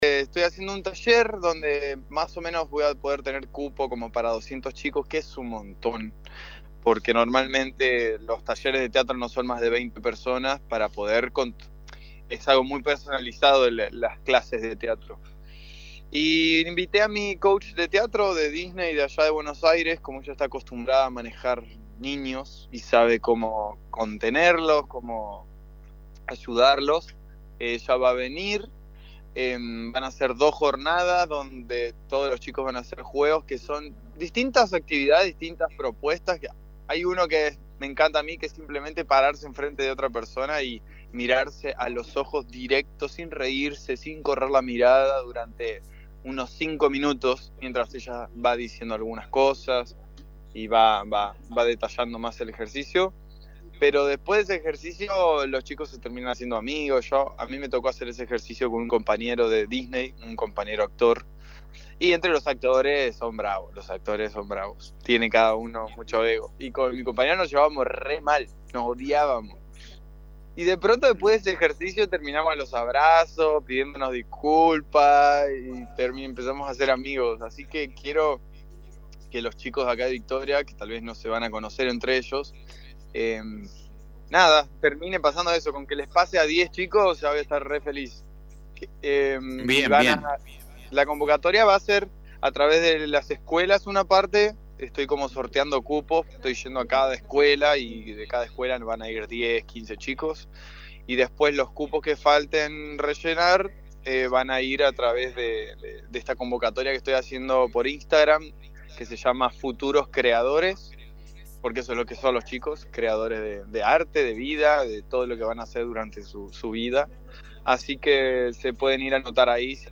En la charla periodística comentó que la convocatoria se esta centrando a través de la página de Instagram “Futuros Creadores”, y a través de las escuelas.